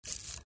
Здесь собраны забавные и неожиданные аудиоэффекты: от прыжков до едва уловимого шуршания.
Звук блохи забирающейся глубоко в волосы